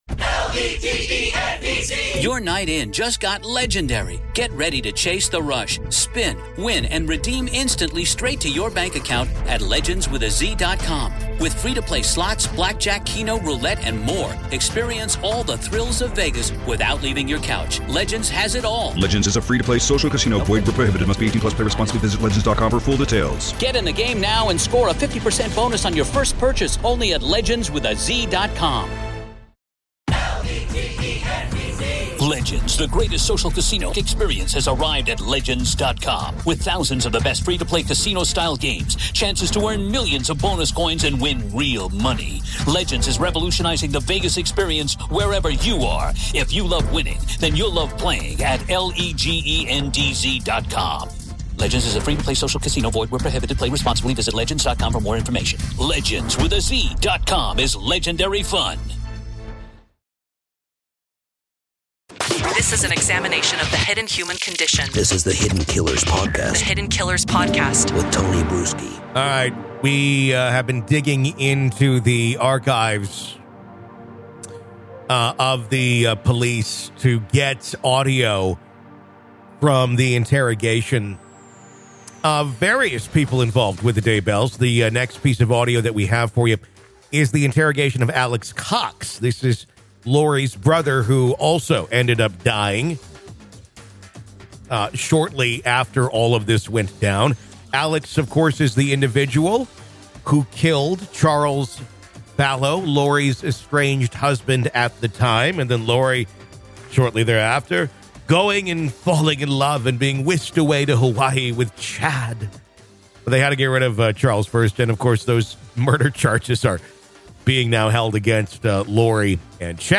police interview